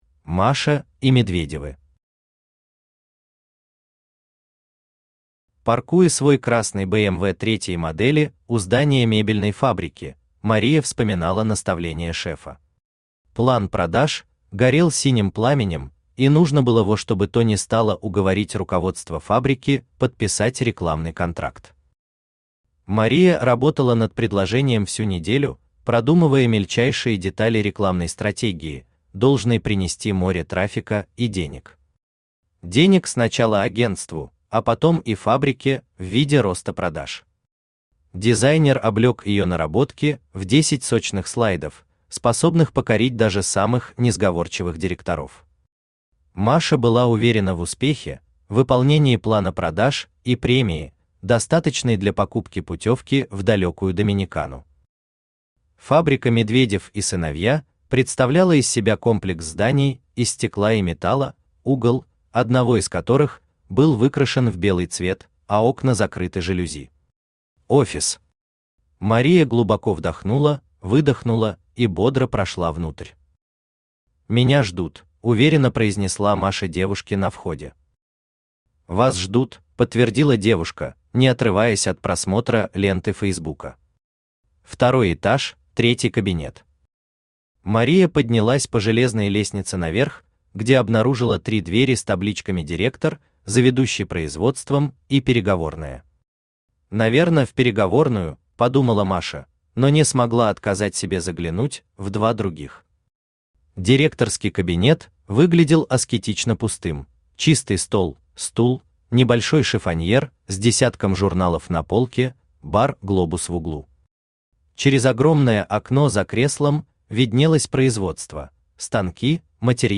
Аудиокнига Маркетинговые сказки | Библиотека аудиокниг
Aудиокнига Маркетинговые сказки Автор Дмитрий Шахов Читает аудиокнигу Авточтец ЛитРес.